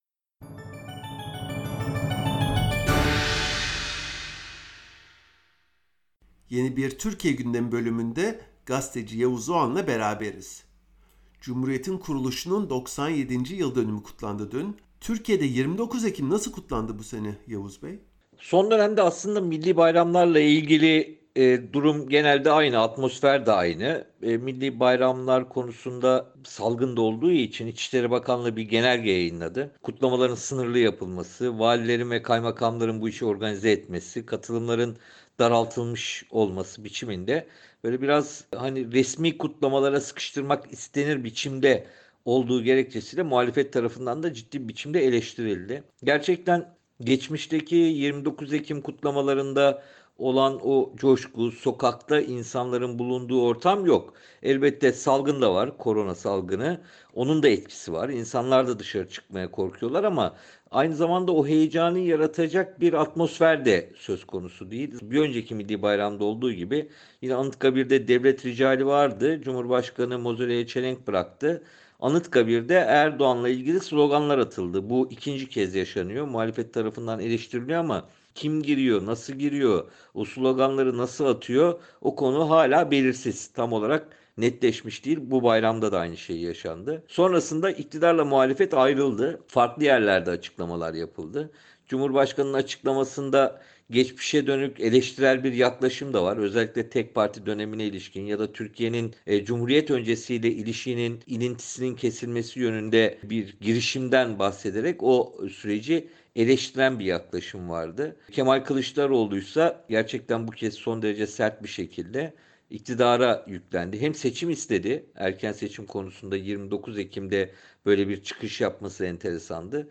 COVID-19’da vaka sayısını bile bilmeyen Türkiye’de halka, daha sıkı tedbirler almaları söyleniyor. Ekonominin iyi durumda olduğu söylenirken TL tarihi dipte. Gazeteci Yavuz Oğhan Türkiye gündemini değerlendirdi.